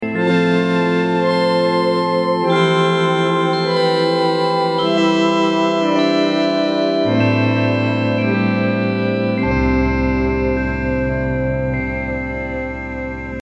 Pure Ear Candy :) Digital Sound Effects Free Download